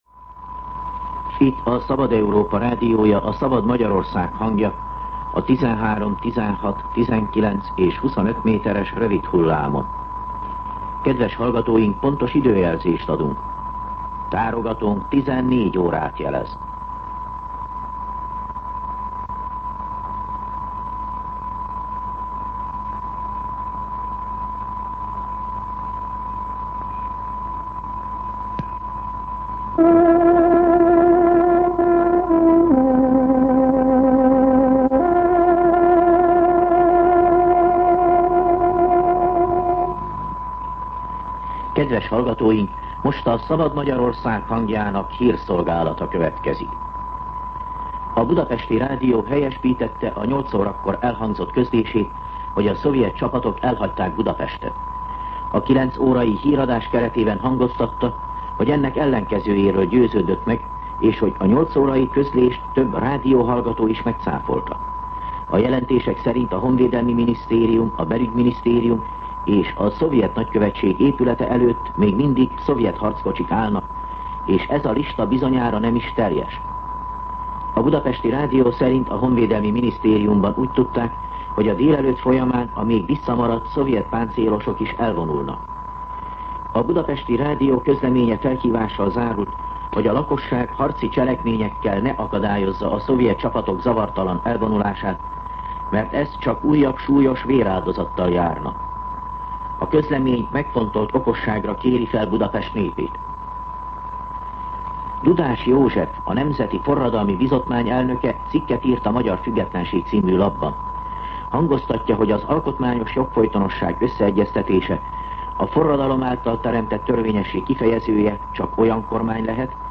14:00 óra. Hírszolgálat